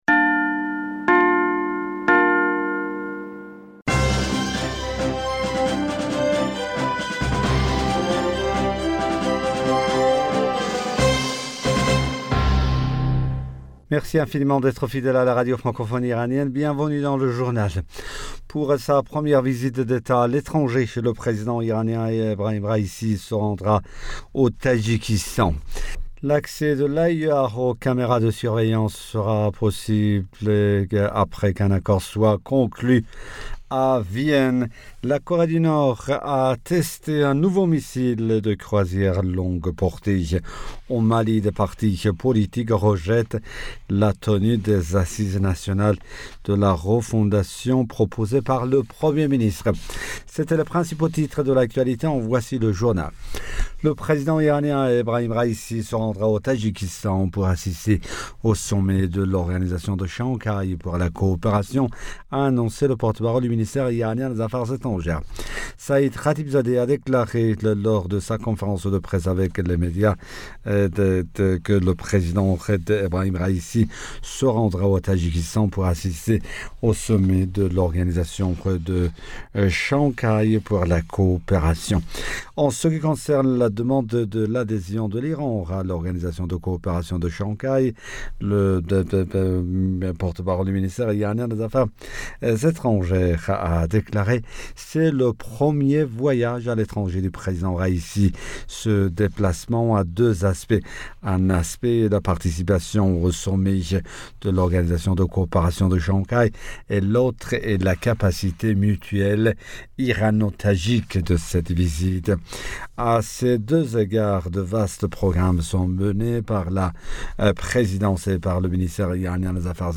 Bulletin d'information Du 14 September 2021